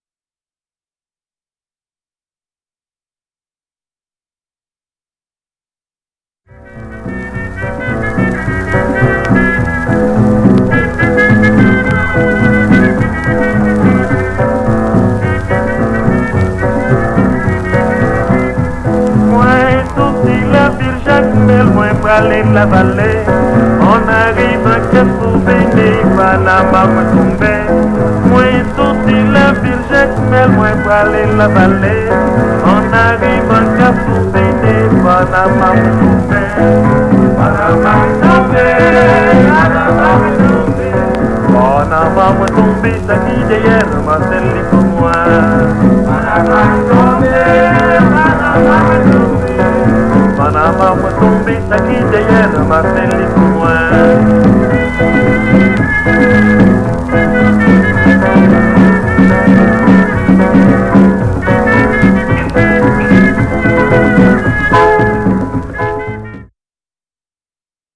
Une des méreng d’Haïti les plus connues